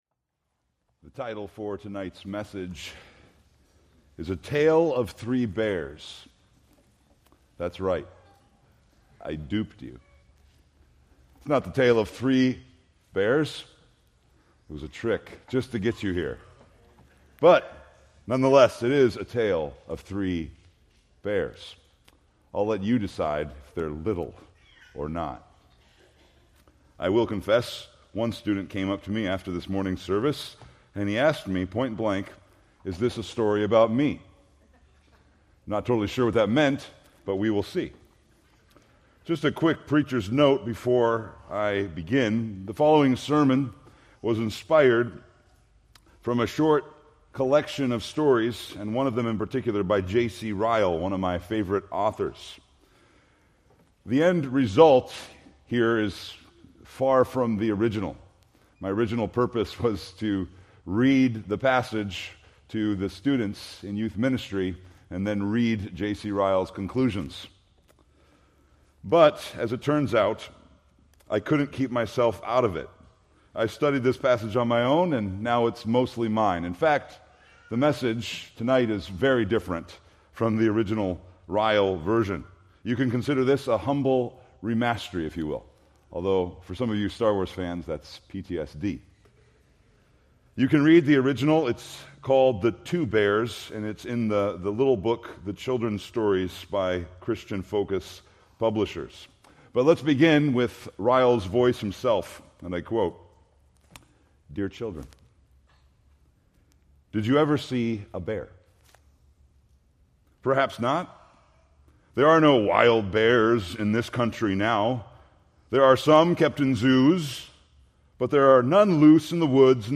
Preached July 13, 2025 from 2 Kings 2:23-25